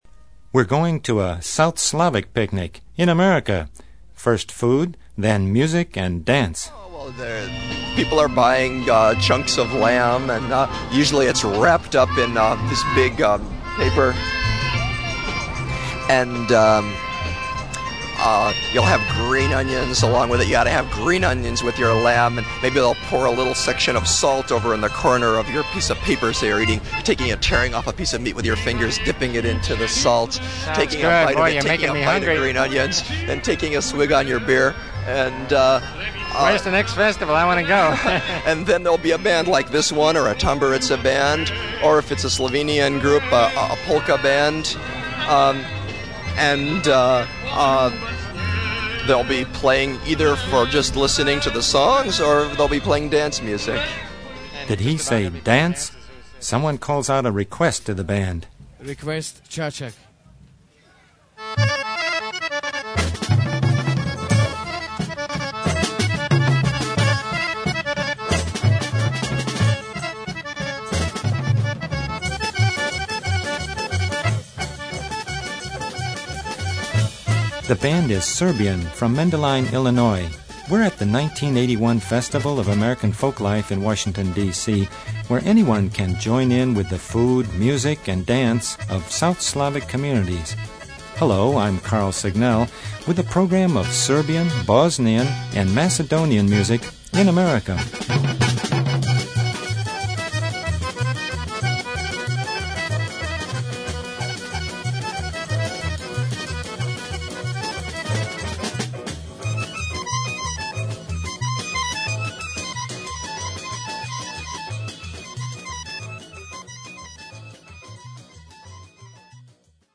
SOUTH SLAVS   Music of Bosnia, Macedonia, and Serbia in Illinois